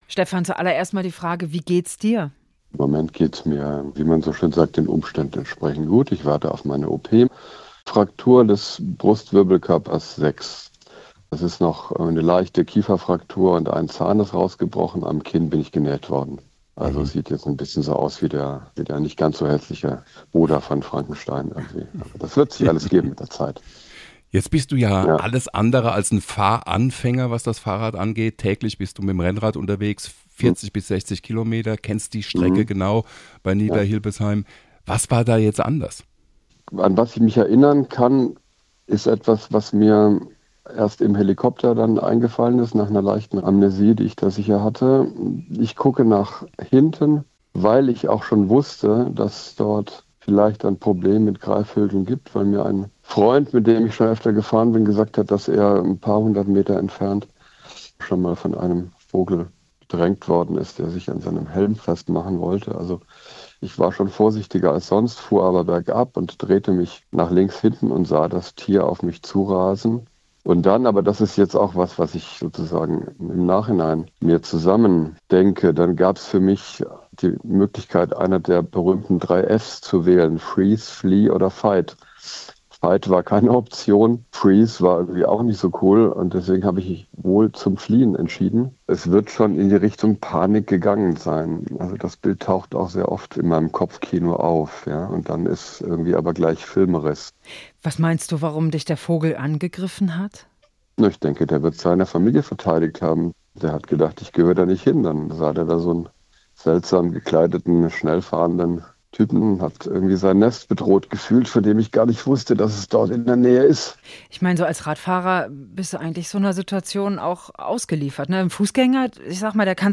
Auf der Flucht vor dem Tier stürzte er und wurde dann mit einem Rettungshubschrauber in ein Krankenhaus gebracht. Dort haben wir ihn für ein Gespräch erreicht.